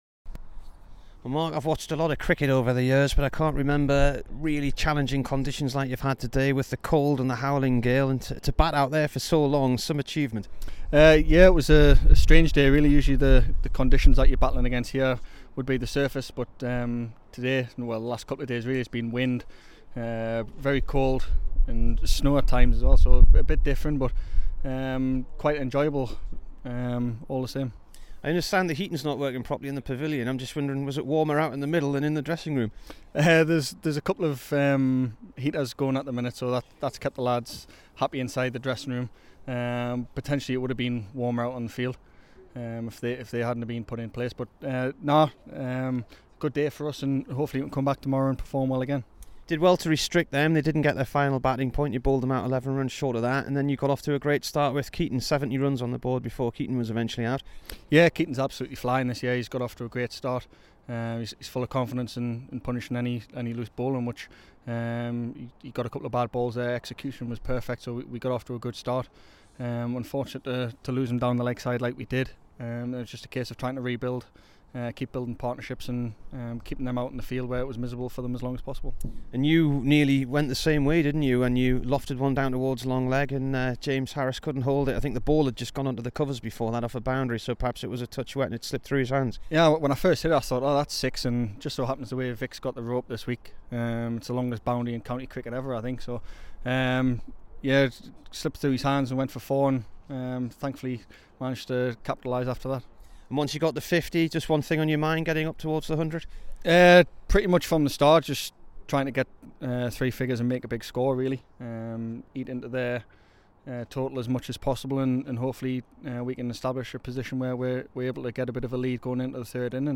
Mark Stoneman int